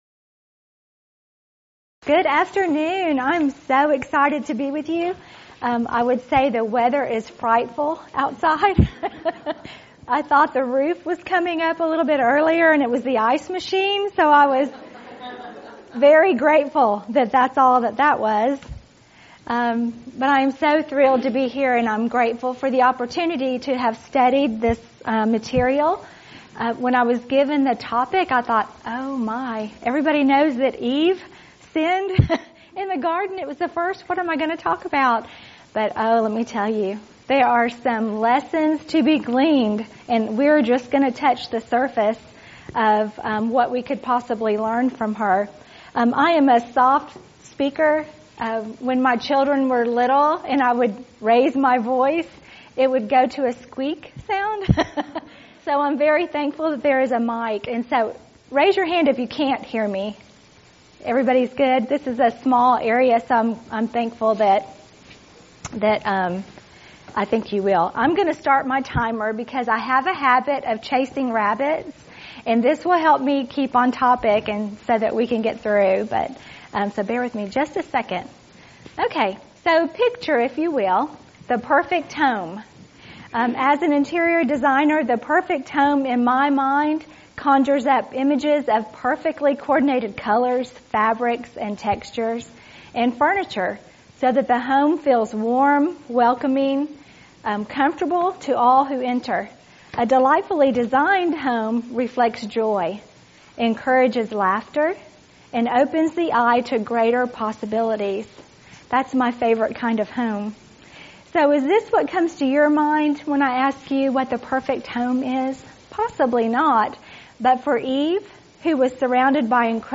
Event: 16th Annual Schertz Lectures Theme/Title: Studies in Genesis
lecture